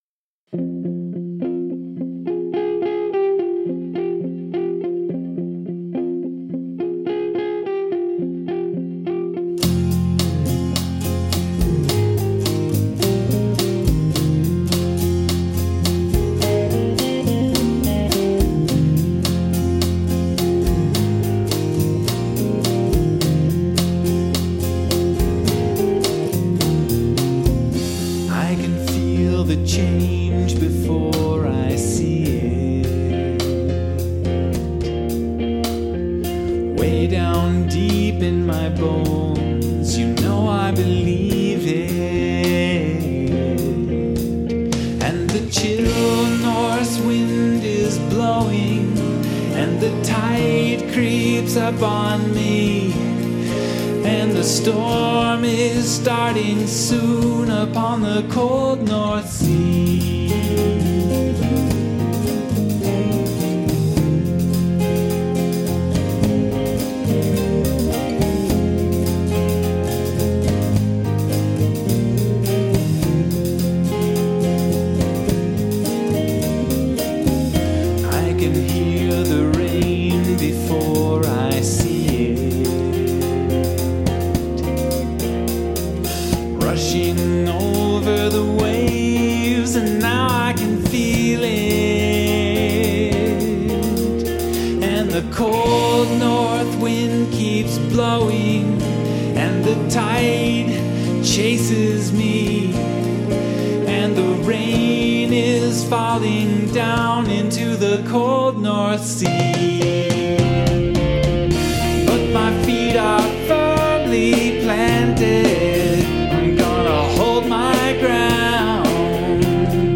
keyboards and synths, drums and percussion
vocals, guitars, bass, slide guitar, synths
• Synths: Roland FA-06
• Effects: 1 (shimmer reverb on the slide guitar)